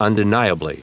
Prononciation du mot undeniably en anglais (fichier audio)
Prononciation du mot : undeniably